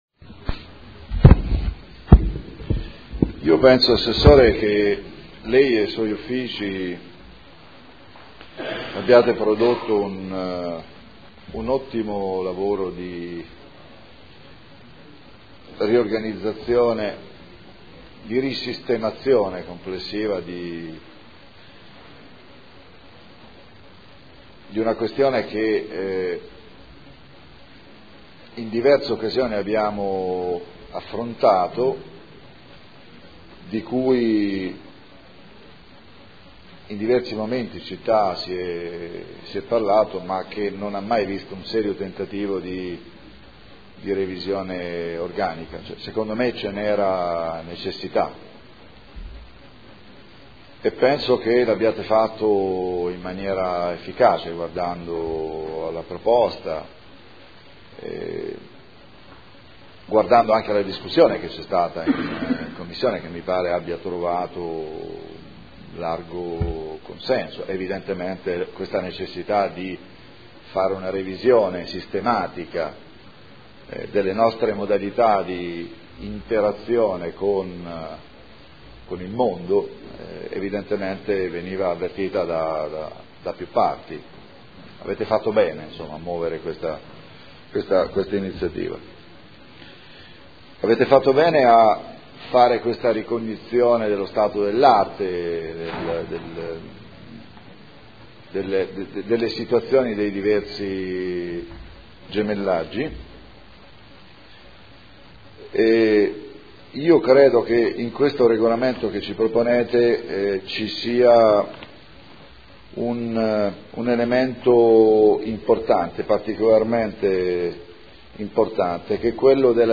Paolo Trande — Sito Audio Consiglio Comunale
Proposta di deliberazione: Regolamento per la costituzione, la gestione e lo sviluppo di relazioni internazionali con città, comunità e territori. Dibattito